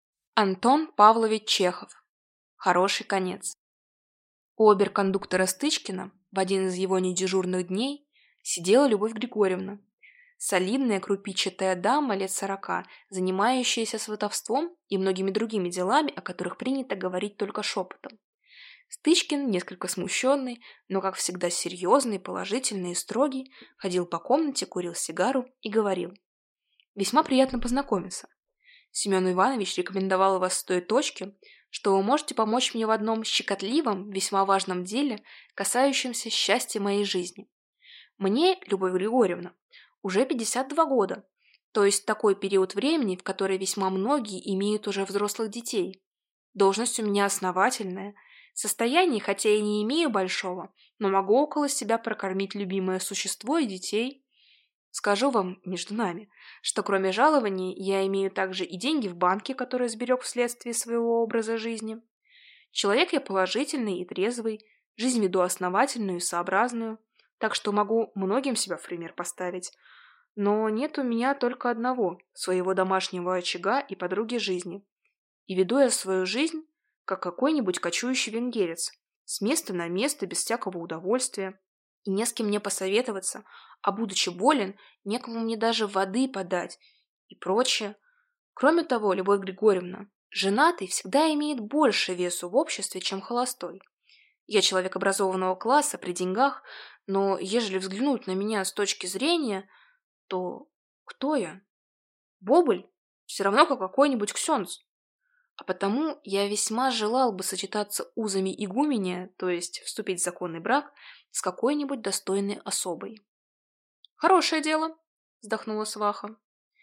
Aудиокнига Хороший конец Автор Антон Чехов Читает аудиокнигу